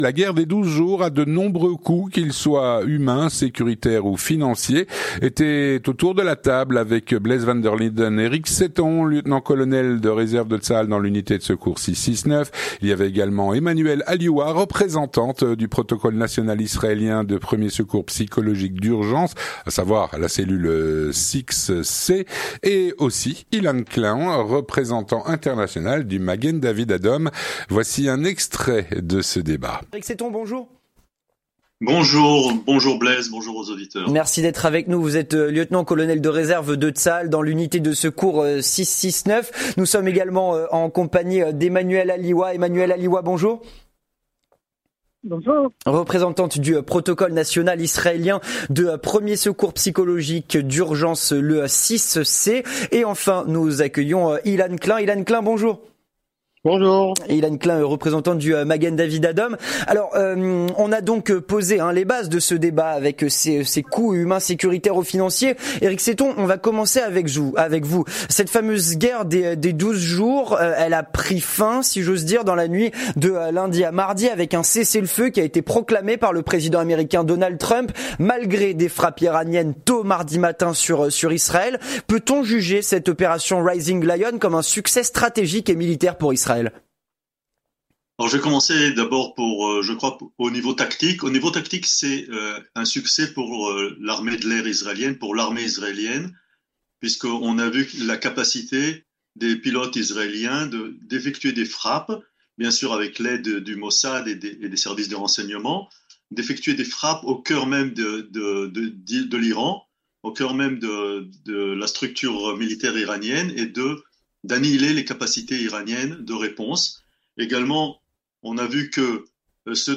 Le grand Débat - La guerre “des 12 jours” a de nombreux coûts qu’ils soient humains, sécuritaires ou financiers.